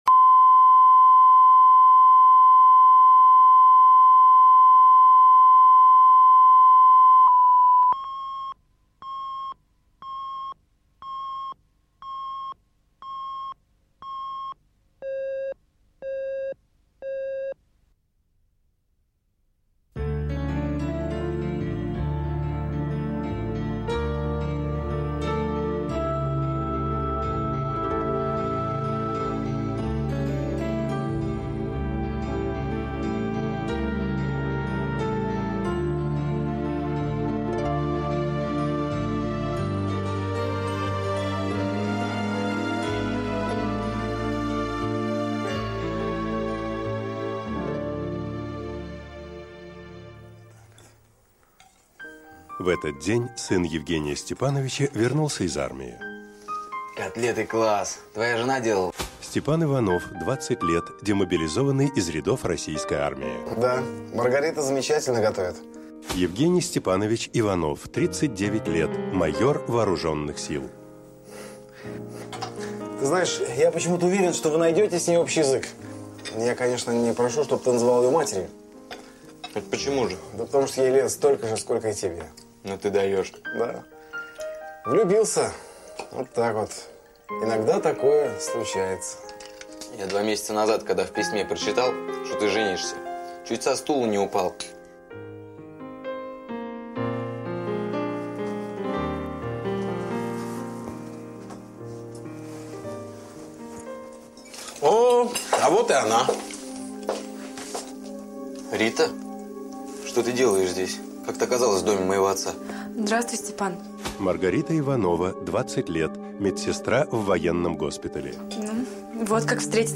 Аудиокнига Мир тесен | Библиотека аудиокниг